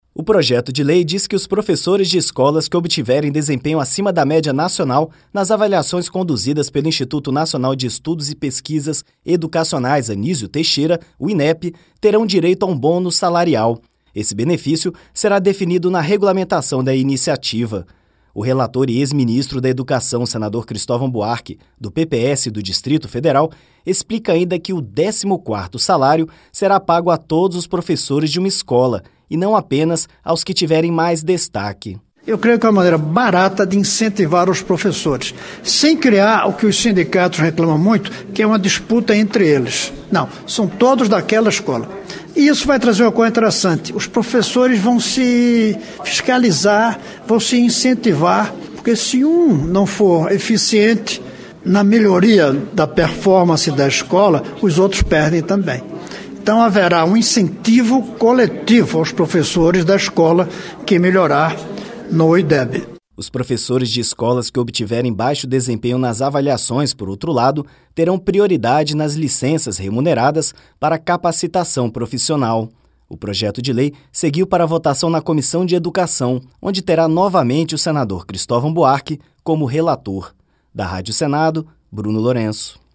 O relator e ex-ministro da Educação, senador Cristovam Buarque (PPS-DF), explicou ainda que o “décimo-quarto salário” será pago a todos os professores de uma escola, e não apenas aos que tiverem mais destaque.